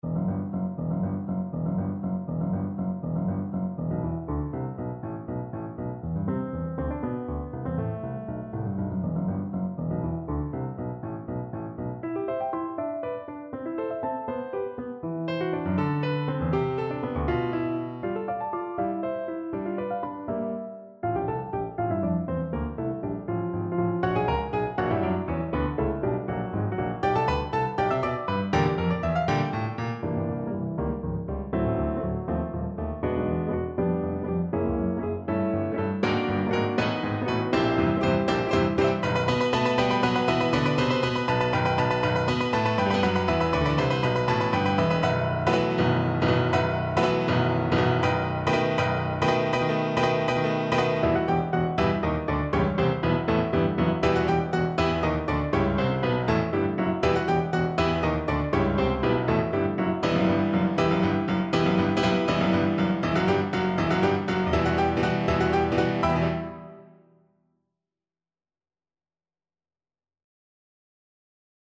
Classical Bruckner, Anton Symphony No.7 Scherzo Viola version
Viola
E minor (Sounding Pitch) (View more E minor Music for Viola )
3/4 (View more 3/4 Music)
~ = 100 Sehr schnell (.=80)
Classical (View more Classical Viola Music)